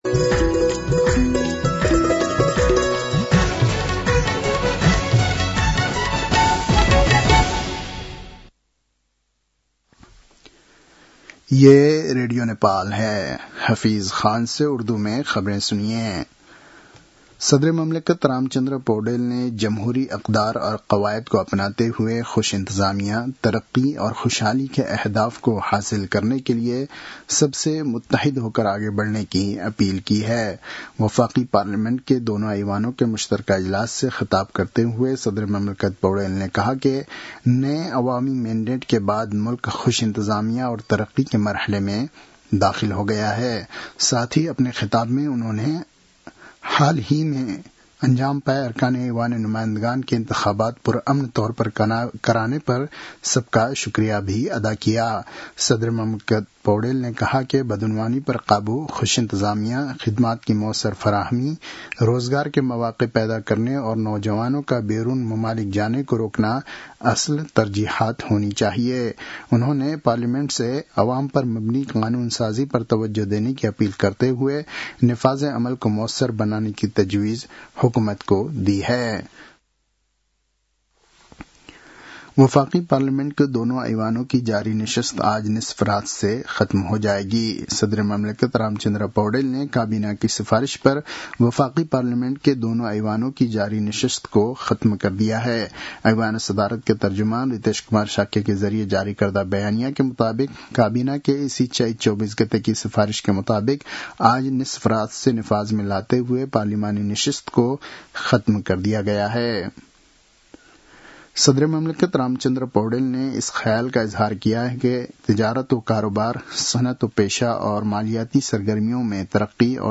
An online outlet of Nepal's national radio broadcaster
उर्दु भाषामा समाचार : २७ चैत , २०८२